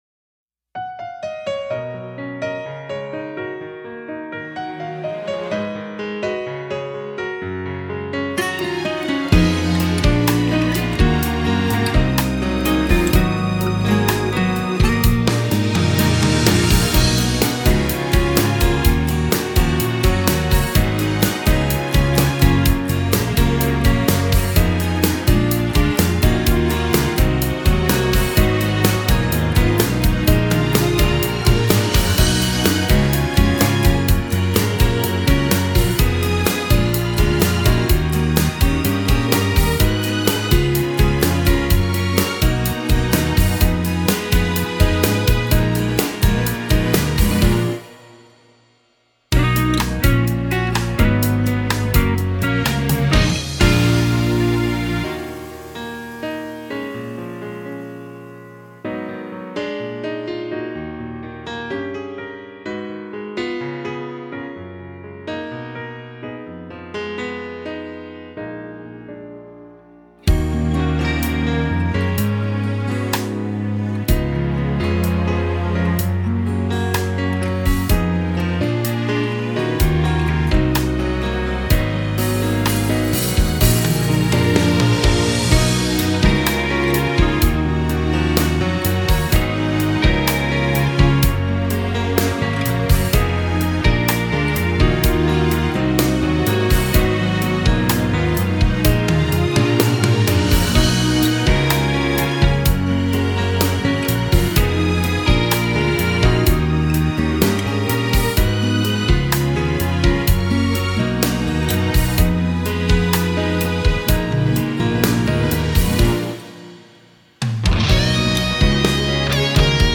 Instrumental version.